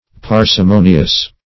Parsimonious \Par`si*mo"ni*ous\, a. [Cf. F. parcimonieux. See
parsimonious.mp3